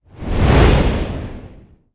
spell-whoosh-2.ogg